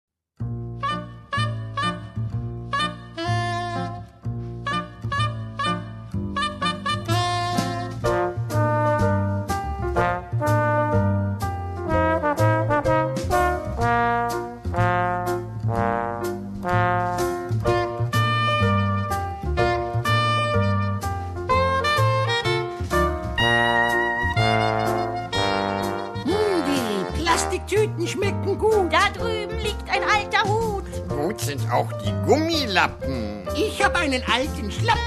mit schmissigen Songs und echt rockiger Musik